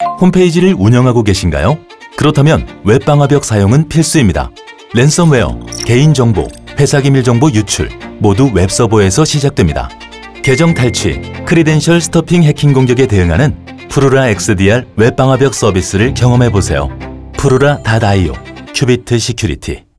📻 라디오